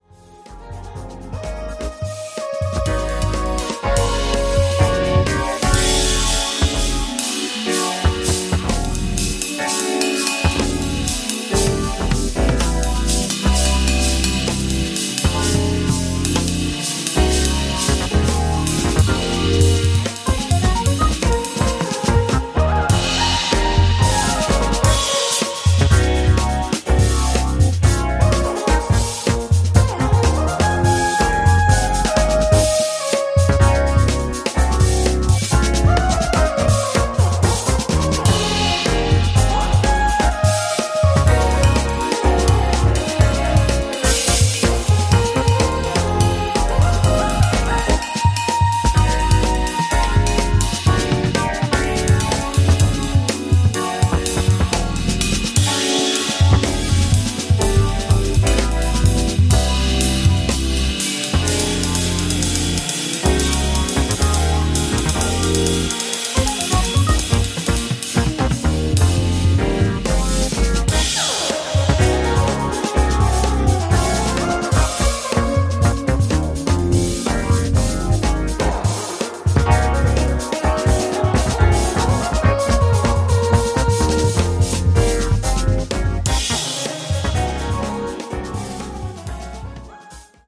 オーセンティックでDJユースなモダン・エレクトリック・フュージョンへと昇華しています。